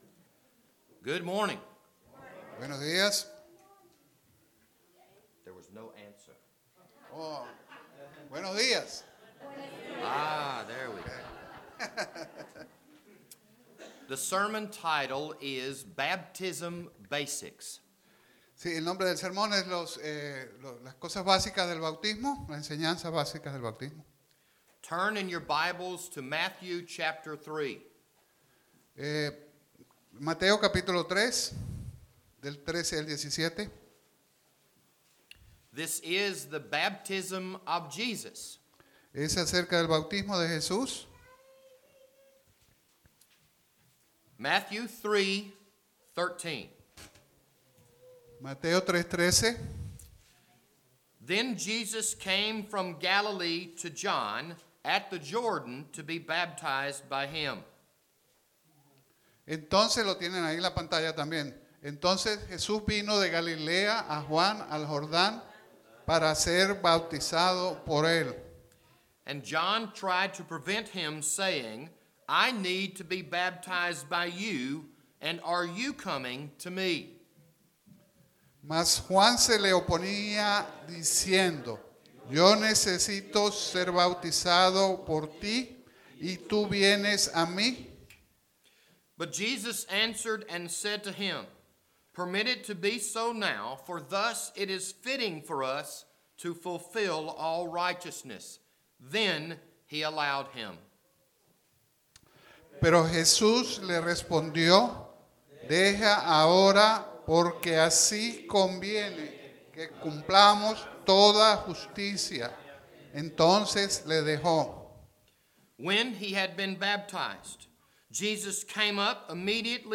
This Sunday evening sermon was recorded on November 11, 2018.